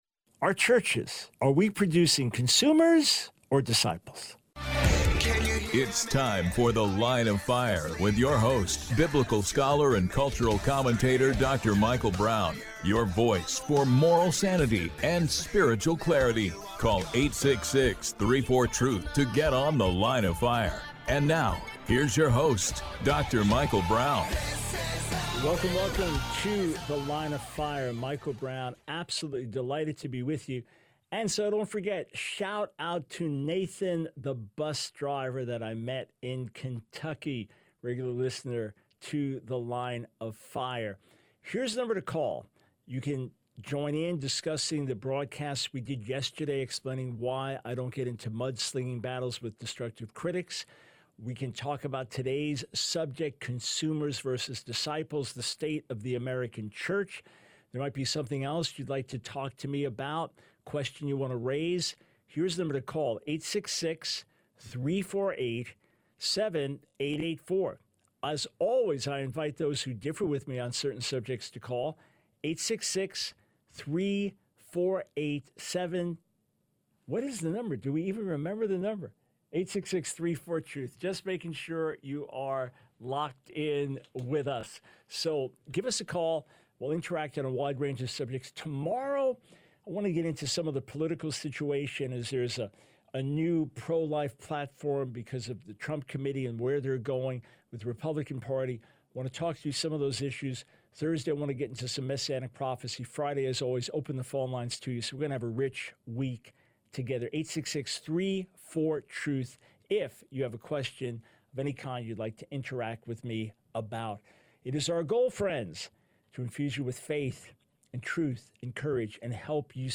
The Line of Fire Radio Broadcast for 07/09/24.